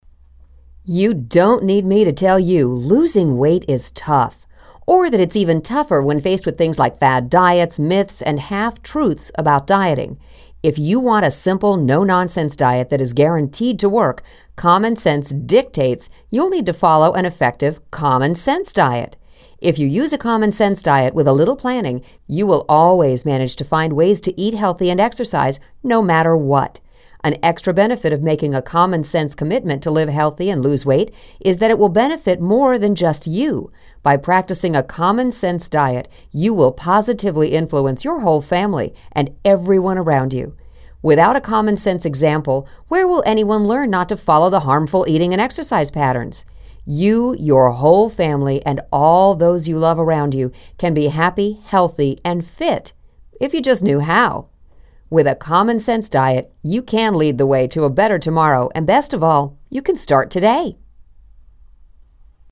Common Sense Weight Loss Audio Book